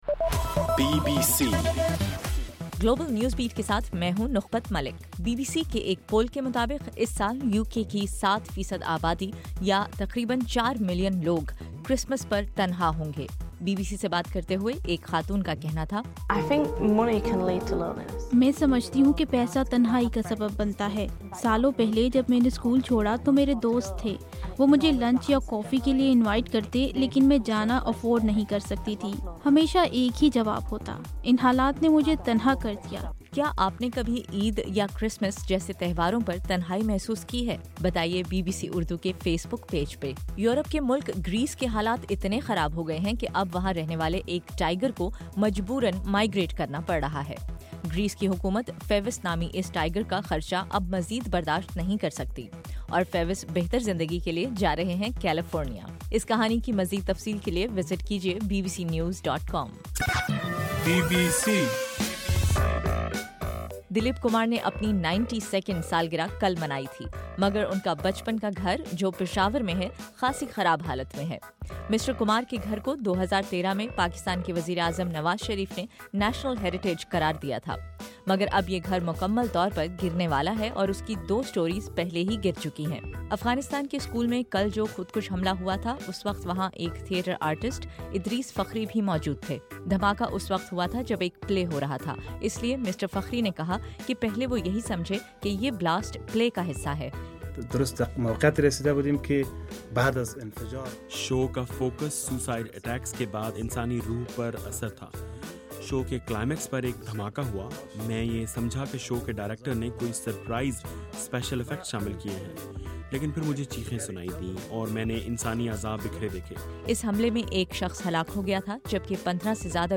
دسمبر 12: رات 10 بجے کا گلوبل نیوز بیٹ بُلیٹن